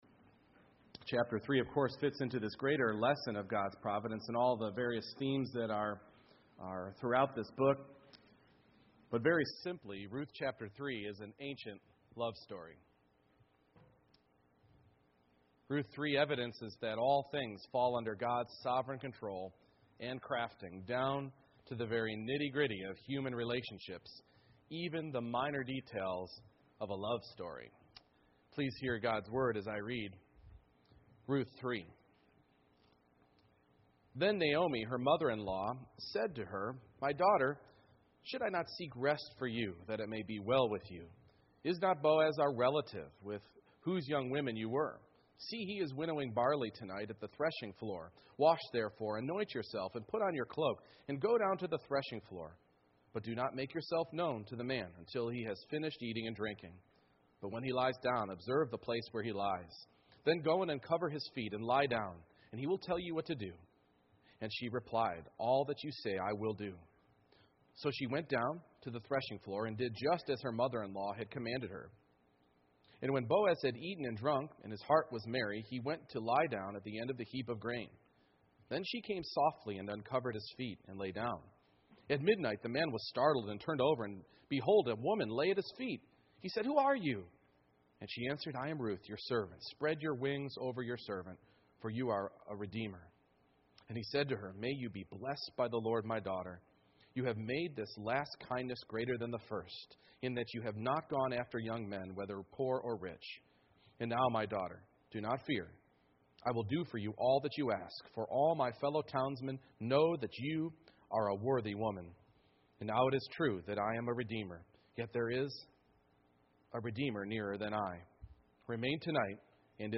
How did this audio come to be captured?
Ruth 3:1-18 Service Type: Morning Worship God's providence includes all things